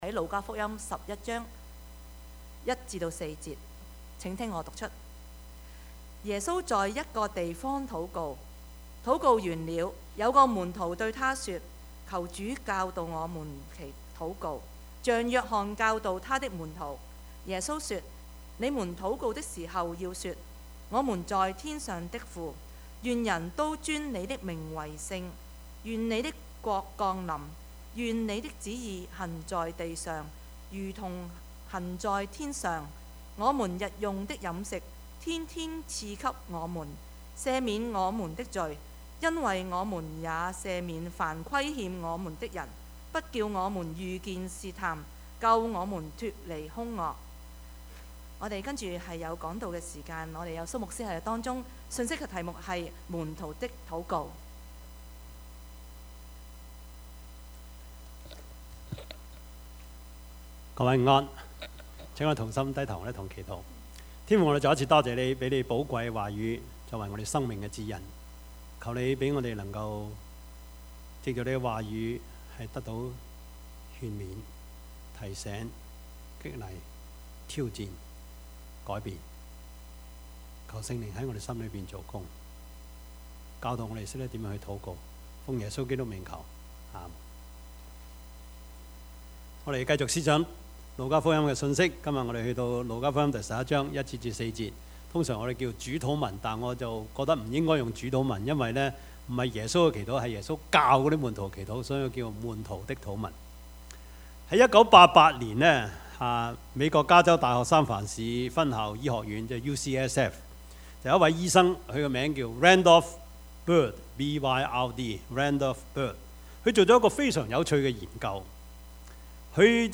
Service Type: 主日崇拜
Topics: 主日證道 « 未知生、焉知死？